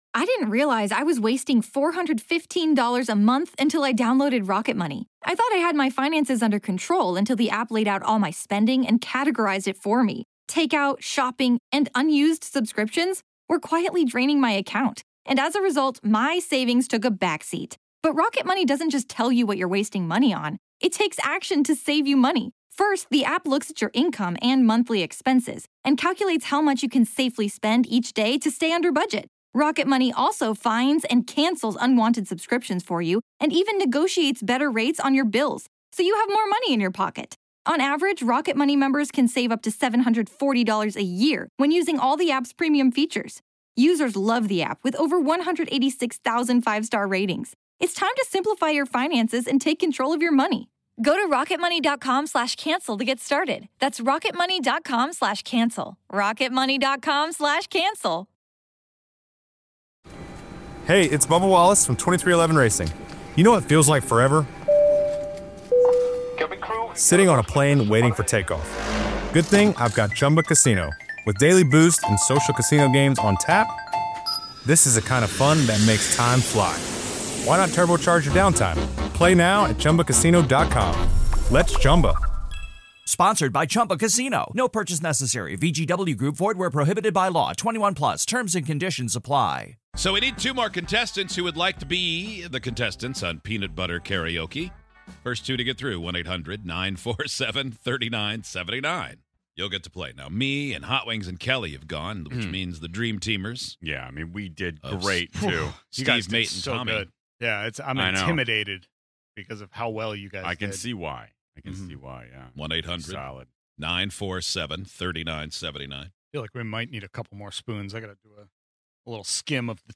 We each put massive globs of peanut butter in our mouths, then step up to the mic to sing karaoke songs of our choosing where only the PB-mouthed person can hear the backing track. Everyone else has to guess what they're attempting to sing.